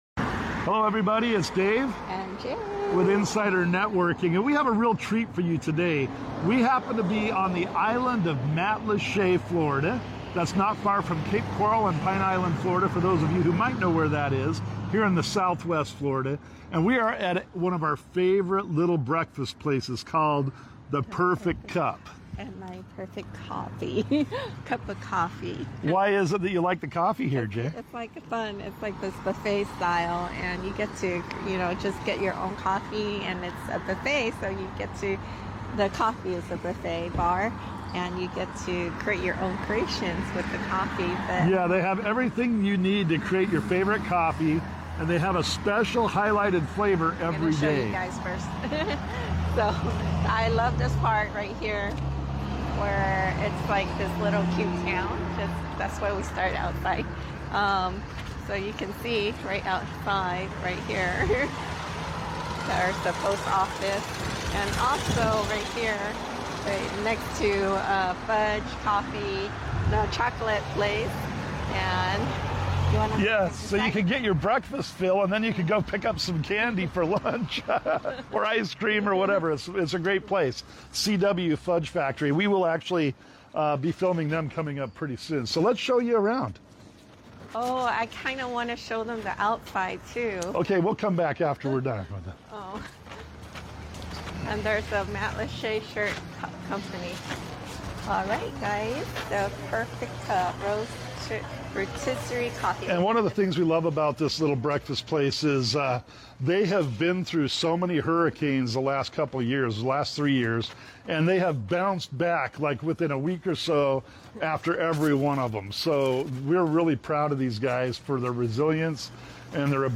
The Perfect Cup Roastery & Cafe on Matlacha Island, Florida | Broadcasting LIVE | Insider Networking by Insider Networking LIVE Broadcasts from Cape Coral, Florida